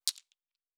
Fantasy Interface Sounds
Objects Small 07.wav